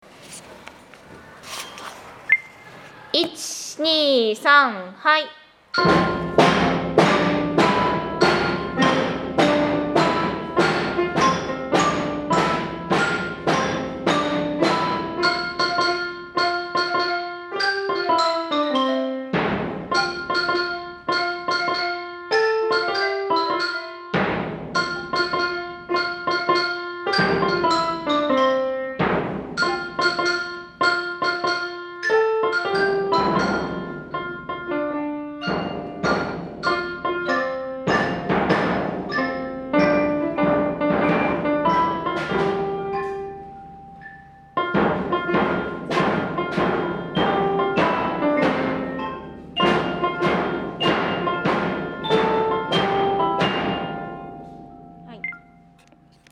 楽器クラブ演奏